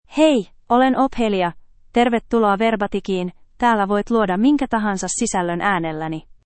Ophelia — Female Finnish AI voice
Ophelia is a female AI voice for Finnish (Finland).
Voice sample
Listen to Ophelia's female Finnish voice.
Female
Ophelia delivers clear pronunciation with authentic Finland Finnish intonation, making your content sound professionally produced.